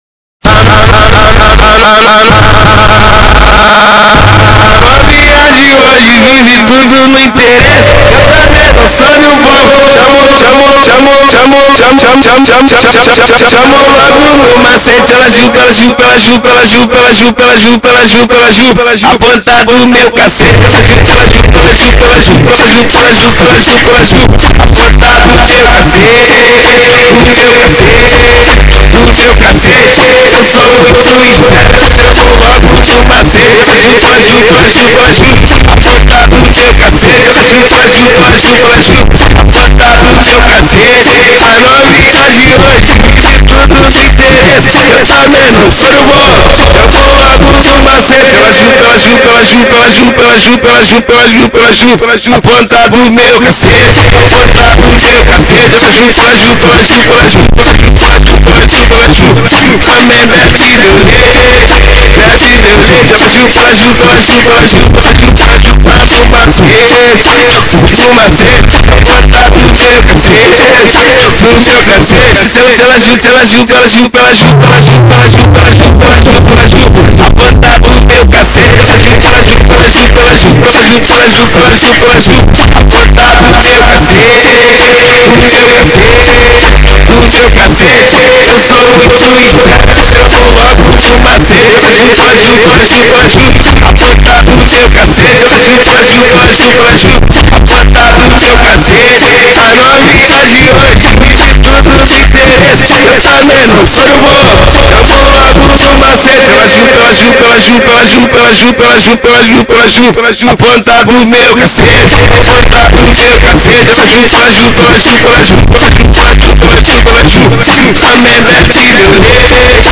Composição: funk.